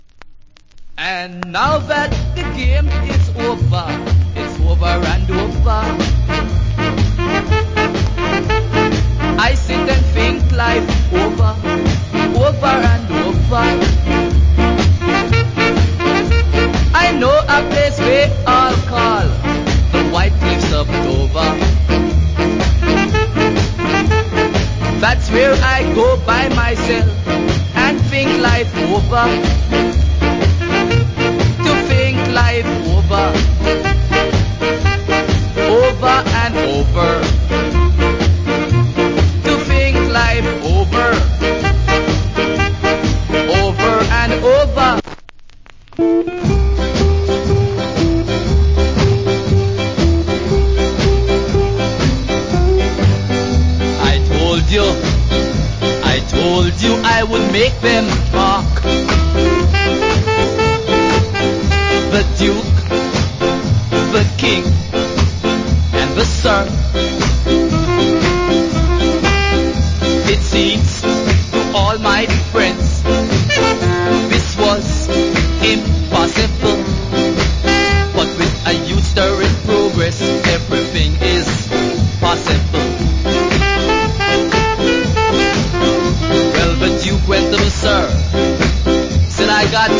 Good Ska.